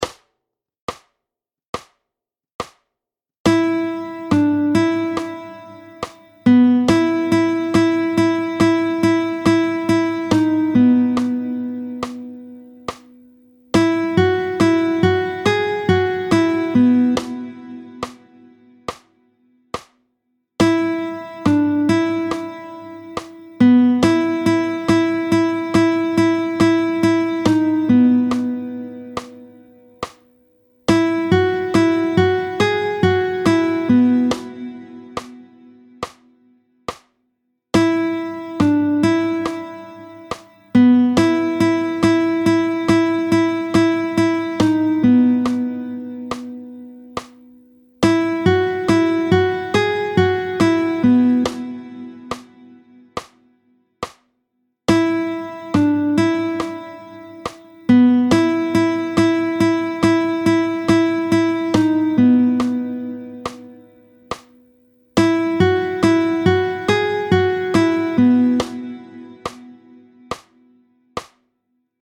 15-02 Mélodie.
tempo 70